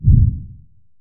lowFrequency_explosion_001.ogg